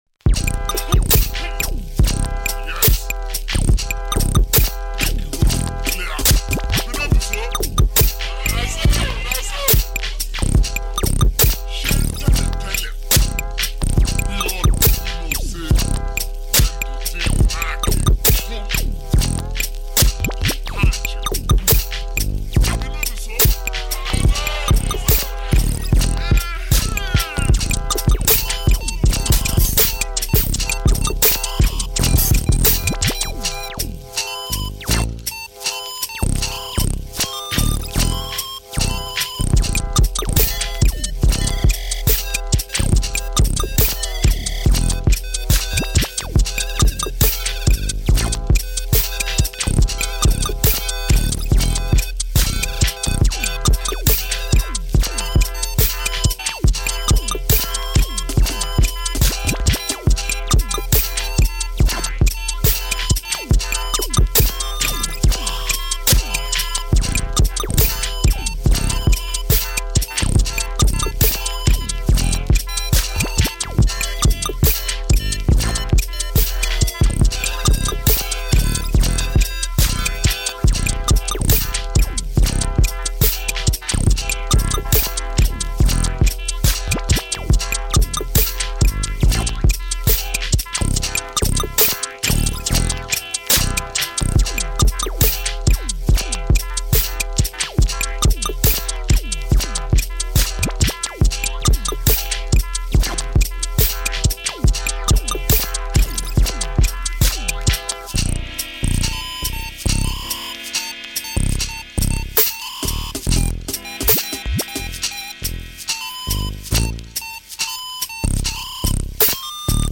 This beat tape